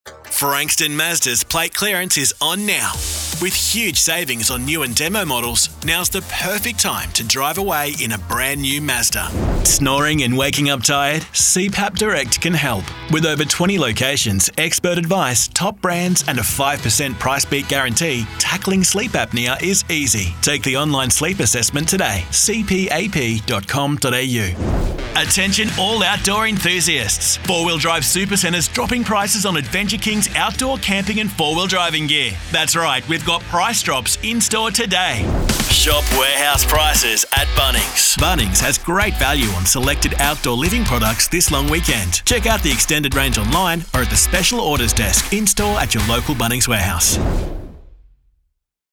Young and fun, sophisticated and natural, pulled back and versatile….  he’s good to go when you’re ready!
• Retail